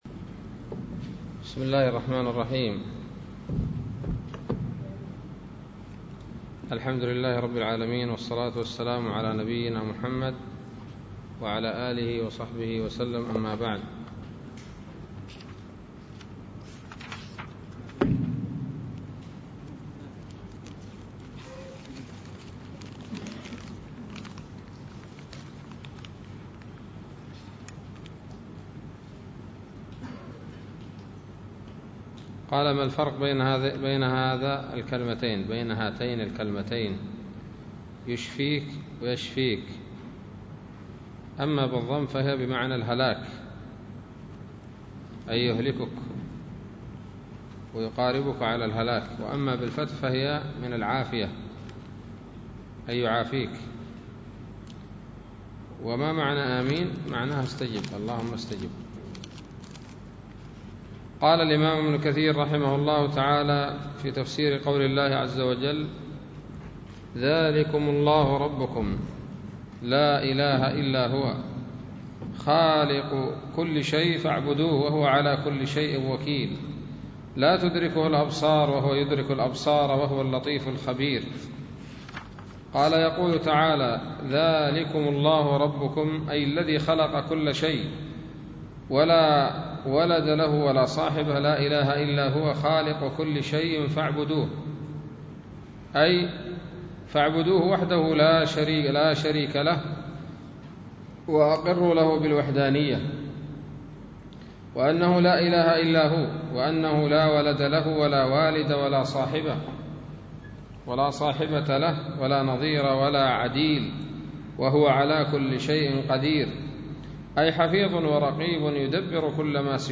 الدرس السادس والثلاثون من سورة الأنعام من تفسير ابن كثير رحمه الله تعالى